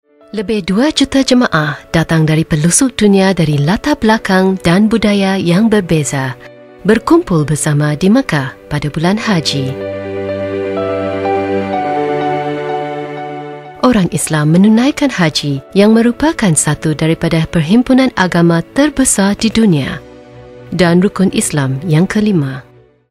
Conversational Trustworthy Energetic Get my Quote Add to quote Invited